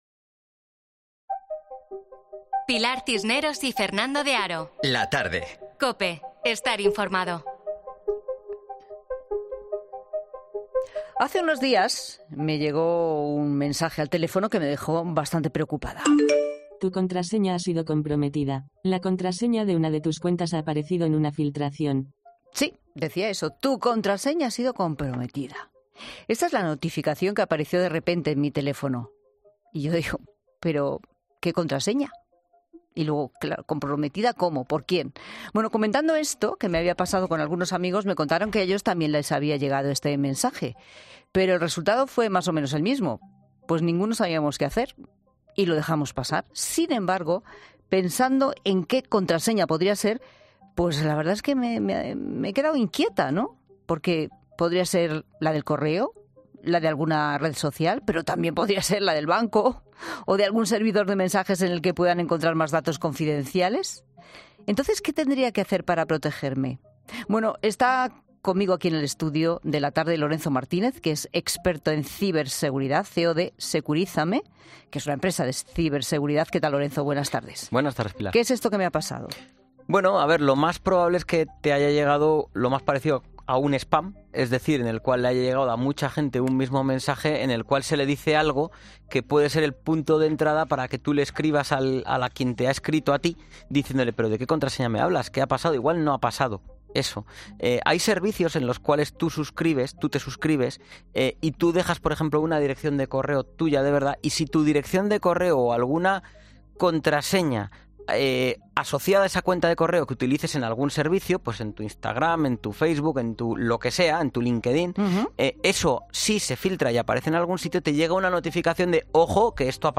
experto en ciberseguridad, explica en 'La Tarde' cómo protegernos ante posibles estafas